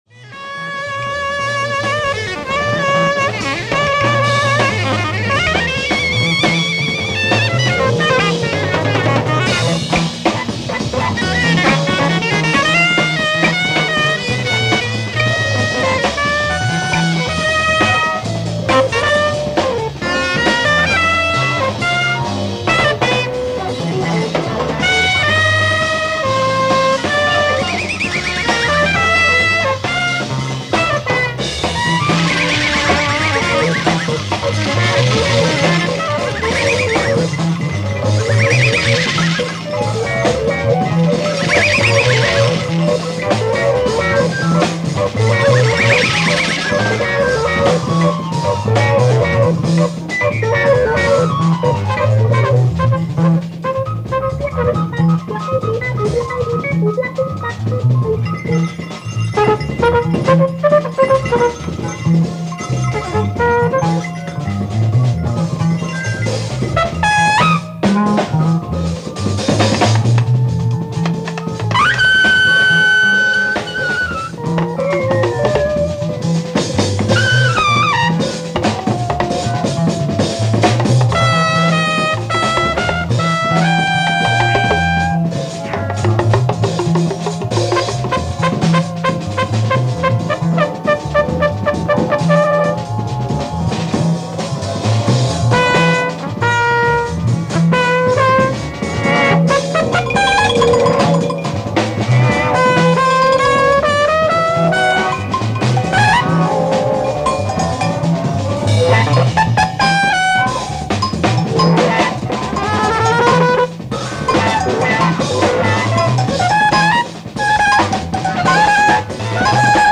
※試聴用に実際より音質を落としています。
AT CBS RECORDS CONVENTION, NASSAU 08/02/1970